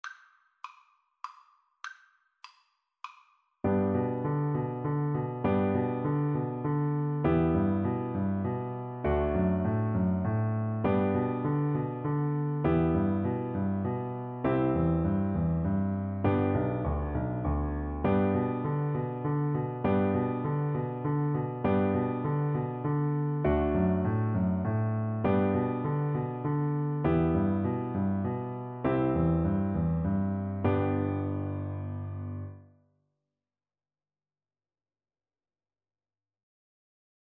Korean folk song
3/4 (View more 3/4 Music)